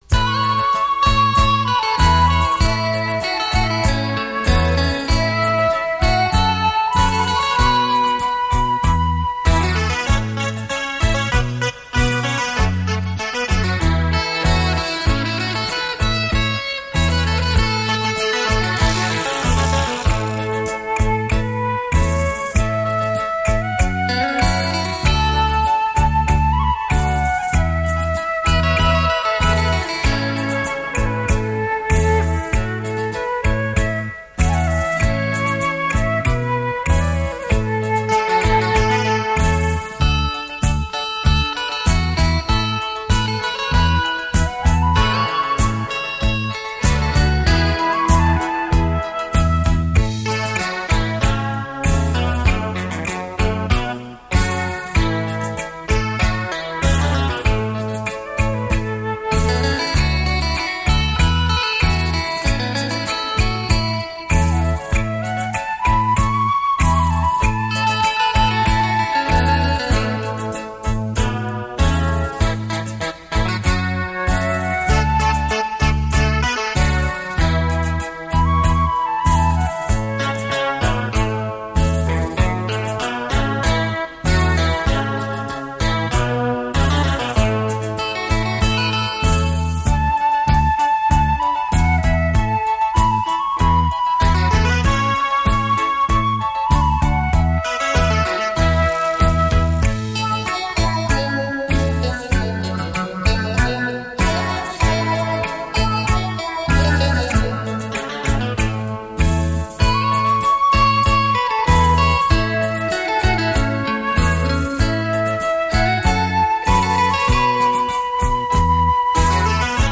这是一张很精典的电子琴音乐唱片
台语名曲、探戈金旋律演奏，优美动听令人回味。